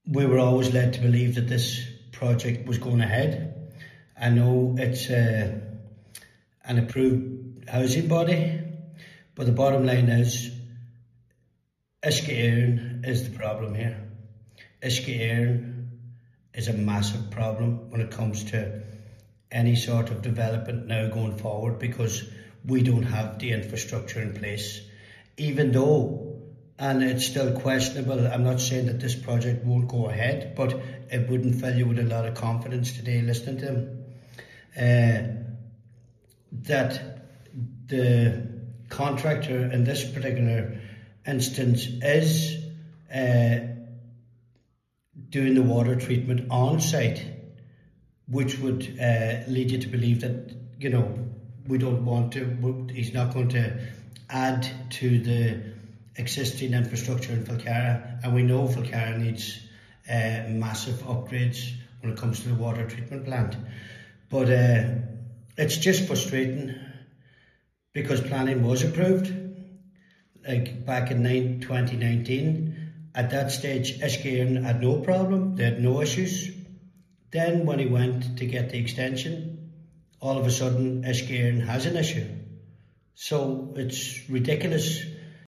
Councillor Denis McGee says the entire project is now in jeopardy as a result: